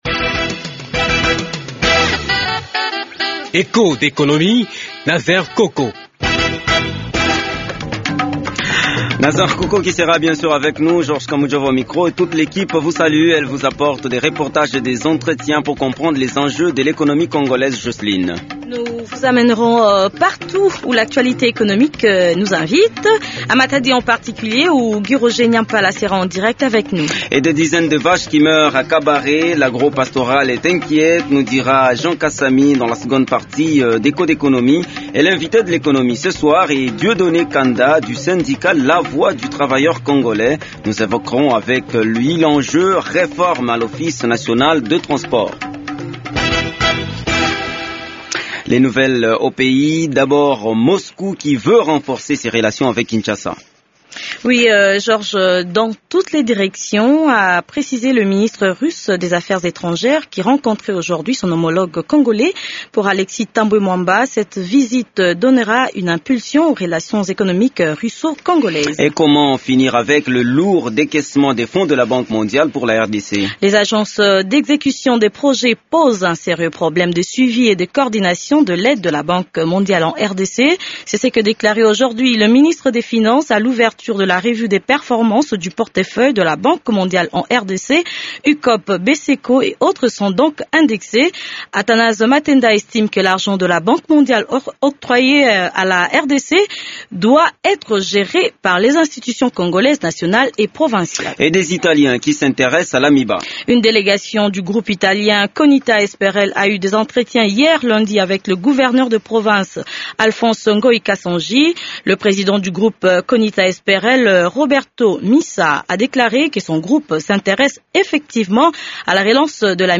Il est l’invité de Echos d’économie de ce soir. tout au long de cette émission, nous en saurons un peu plus sur la nouvelle acquissition d’une nouvelle grue flottante et ses enjeux économiques pour la RDC. toute l’actualité économique du pays et du monde vous est détaillé dans ce magazine.